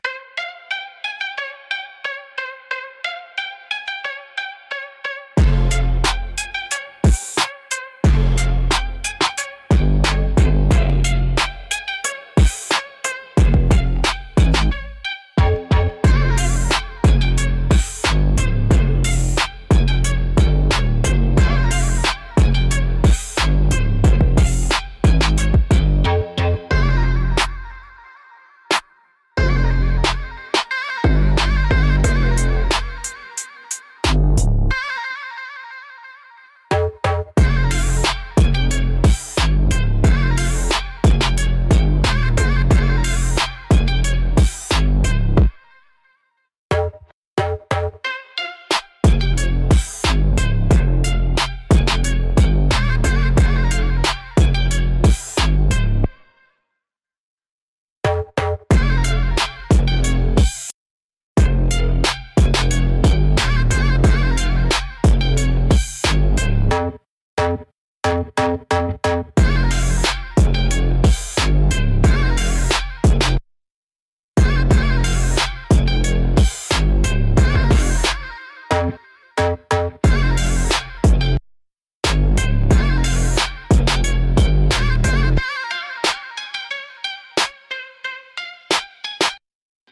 Instrumentals - No Hook, Just Heat